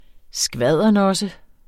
Udtale [ ˈsgvaðˀʌ- ]